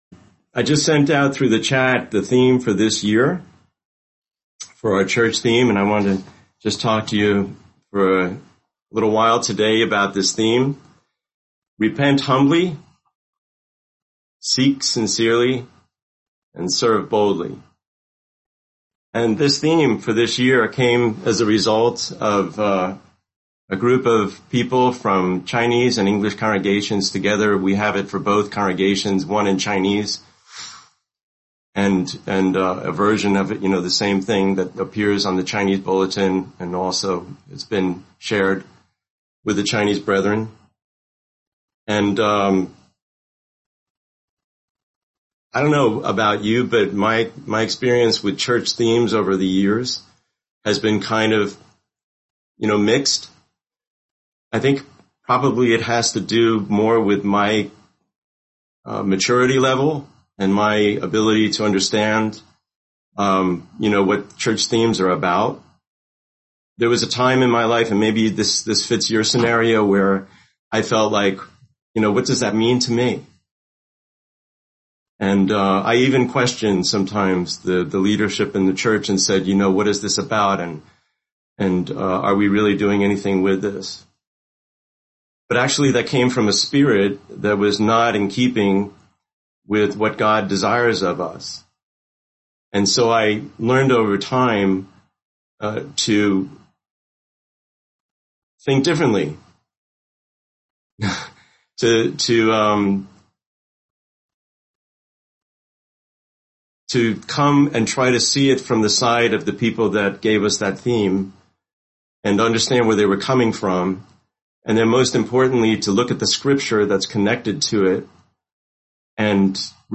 Video: Sunday English Worship Video